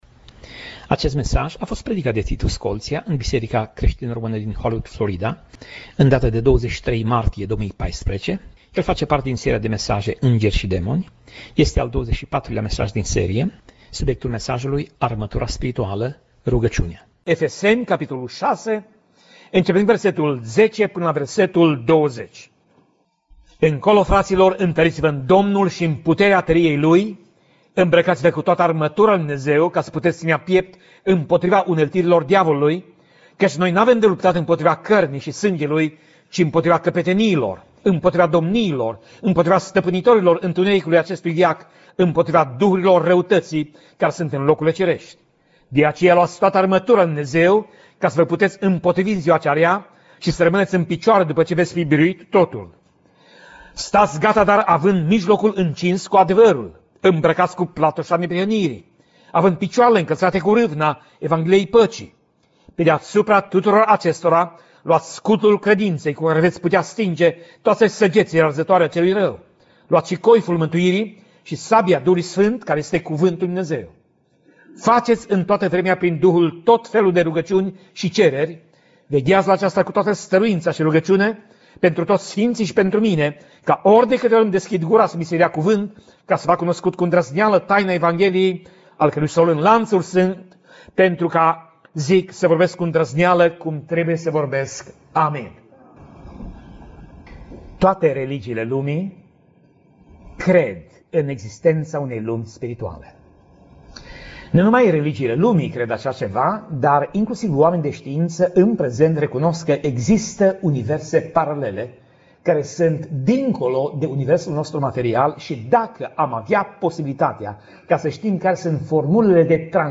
Pasaj Biblie: Efeseni 6:10 - Efeseni 6:20 Tip Mesaj: Predica